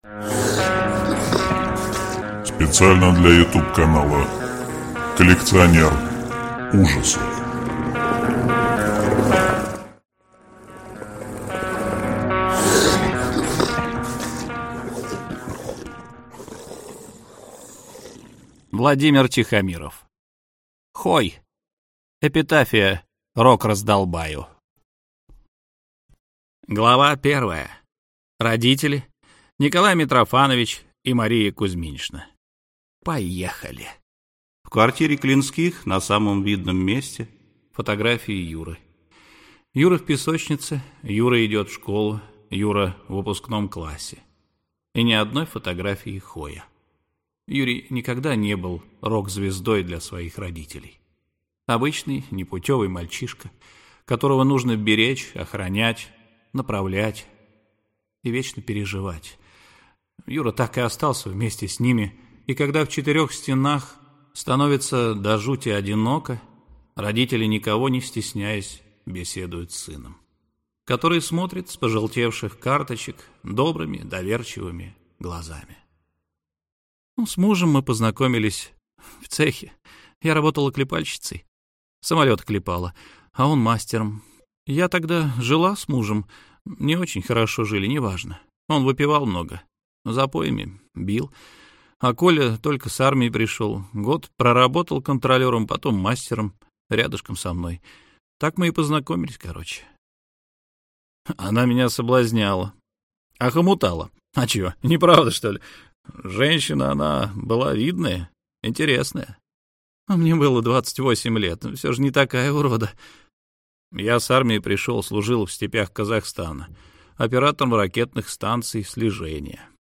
Аудиокнига Хой! Эпитафия рок-раздолбаю | Библиотека аудиокниг